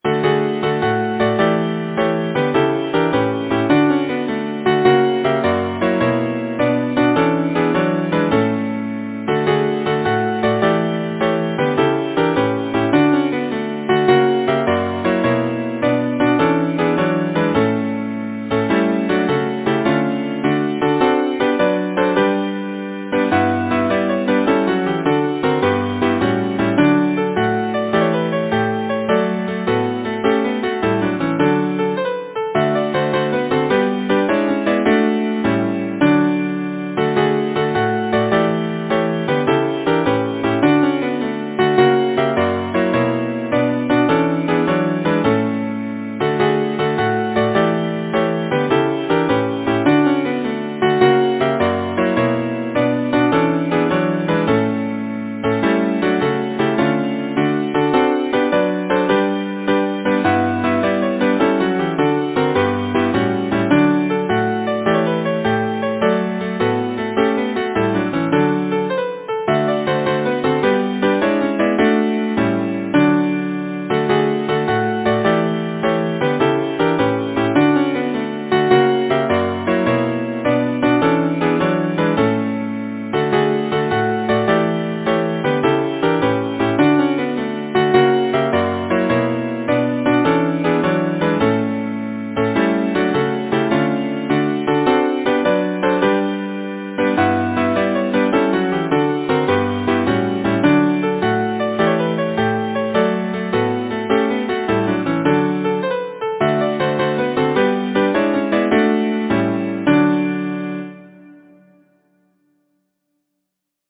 Title: The Spring’s free sunshine falleth Composer: Samuel Reay Lyricist: Anonymous Number of voices: 4vv Voicing: SATB Genre: Secular, Partsong
Language: English Instruments: A cappella